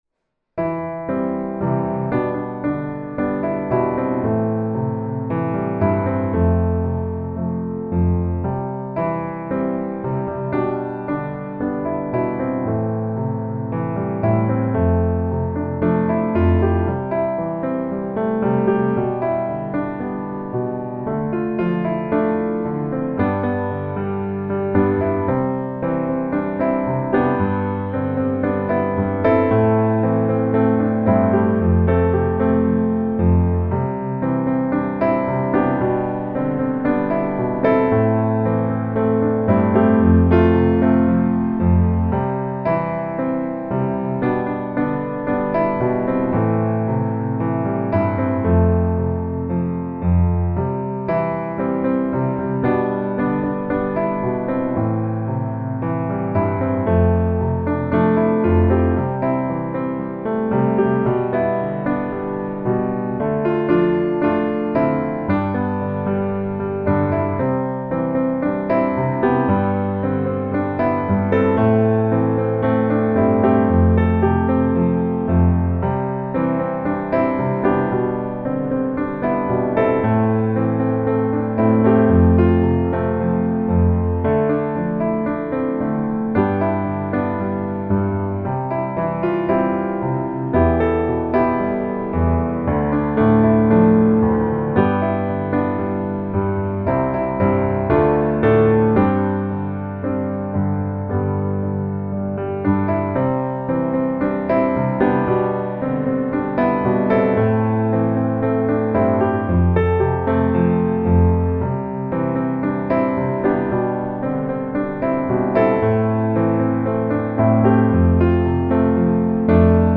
The countermelodies can be sung together with the chorus.
C Major